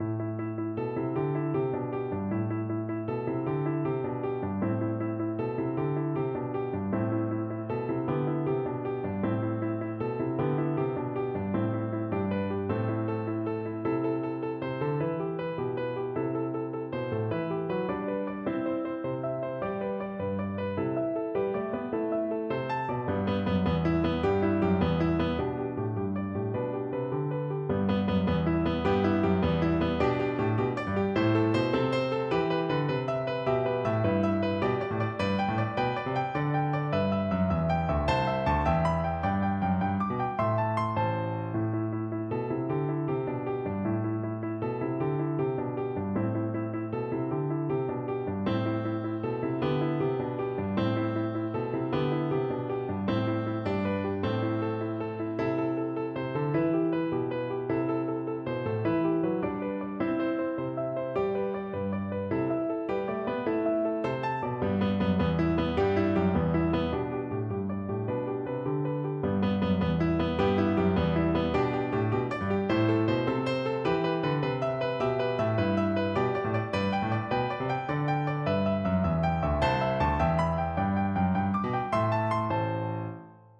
With energy . = c. 104
6/8 (View more 6/8 Music)